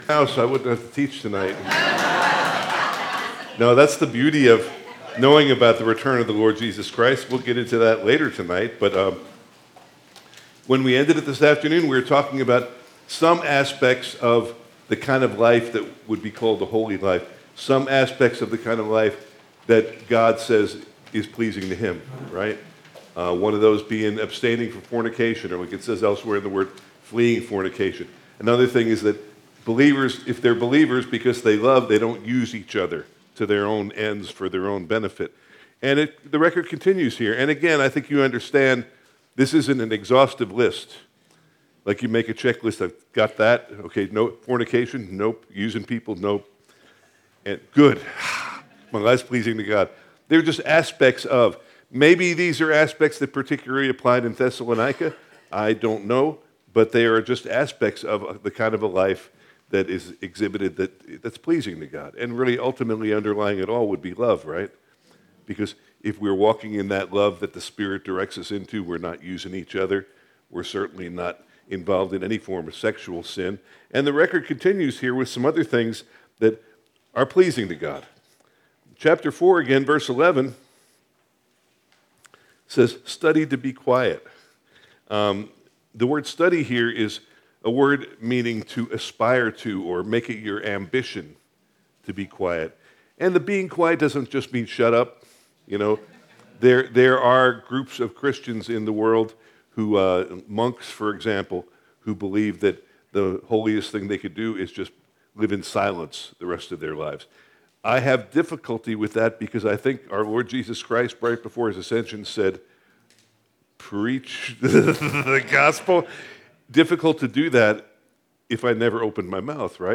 1 Thessalonians 4:11-end Our Daily Hope (Family Camp 2024) – Part 7 July 30, 2024 Part 7 in a verse-by-verse teaching series on 1 and 2 Thessalonians with an emphasis on how our hope helps us to live holy lives until Christ returns.